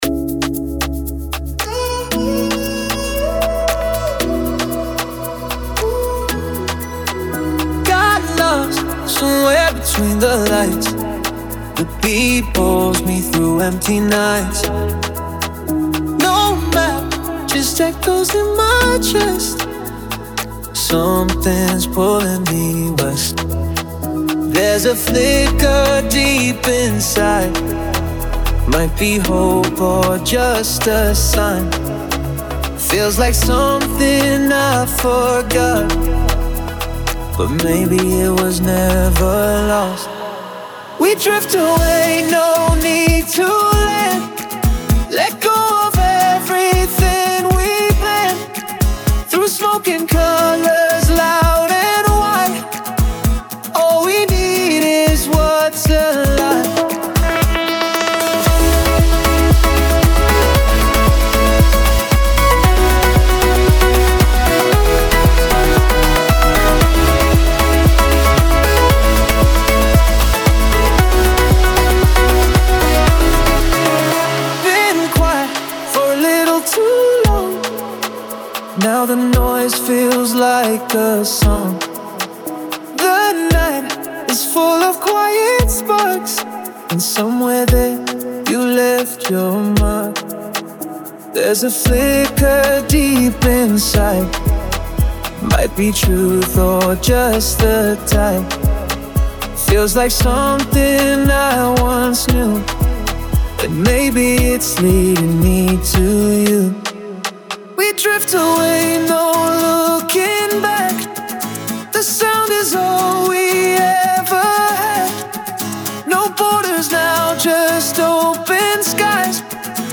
👗 Moderner Fashion Song (Aktivierender Sound)
• Emotion transportieren